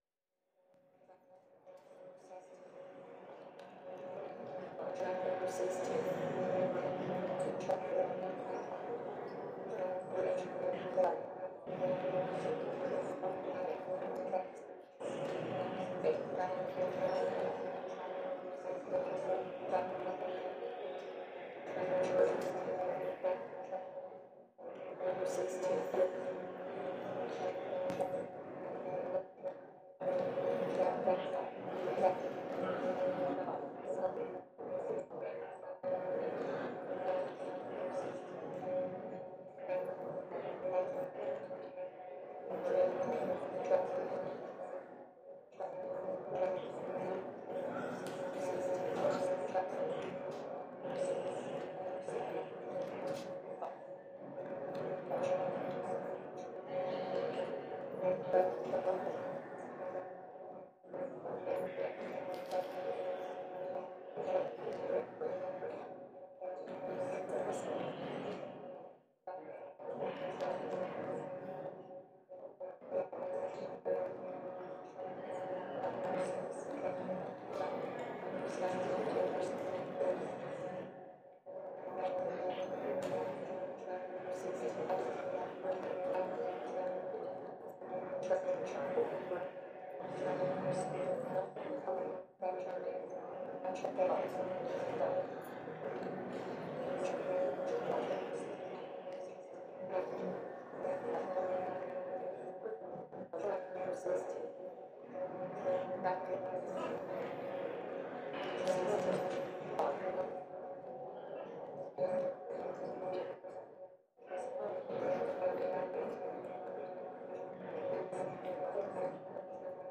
Since I opted to run random float cast operations, and not play around with softening the sound packets created by the 'cuts', the results are much more 'clicky'.
This, oddly, suits the space in which the original sound was captured, a tiled train platform with lots of bounce in the sound that somehow doesn't end up becoming muddy.